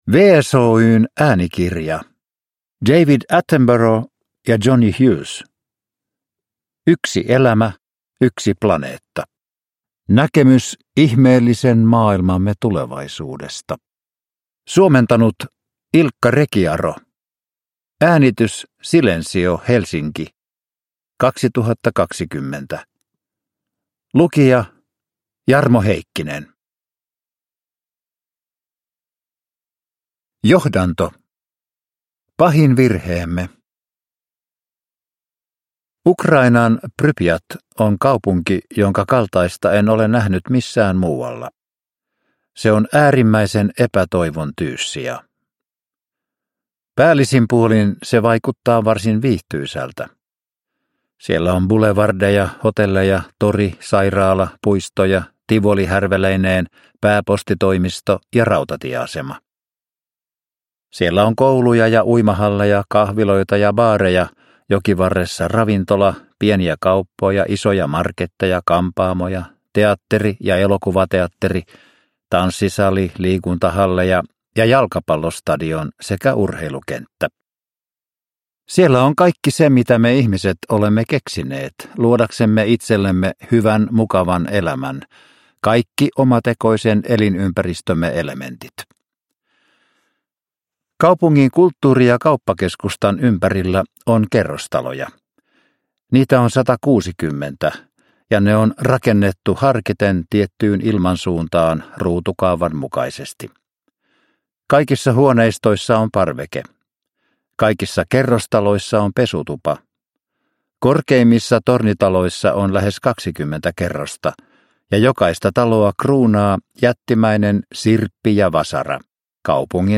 Yksi elämä, yksi planeetta – Ljudbok